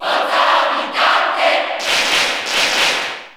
Category: Crowd cheers (SSBU) You cannot overwrite this file.
Villager_Cheer_Italian_SSB4_SSBU.ogg